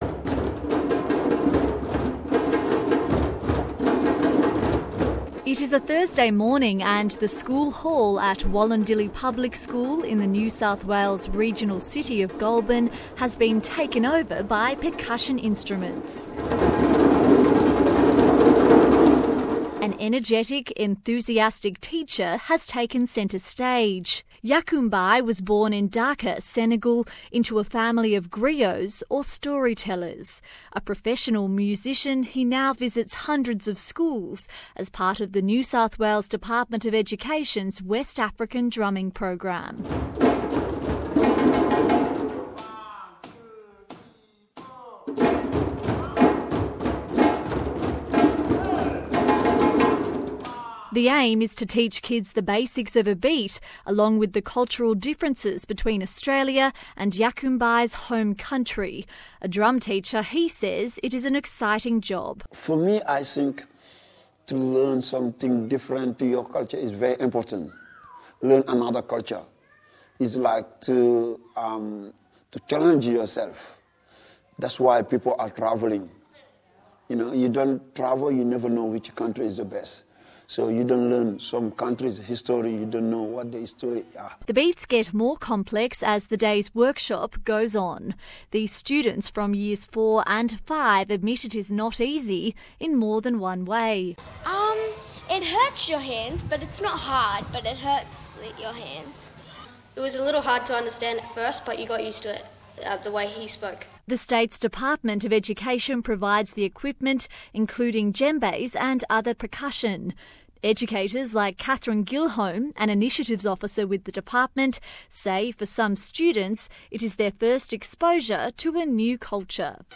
Students learning the drums in Goulburn Source: SBS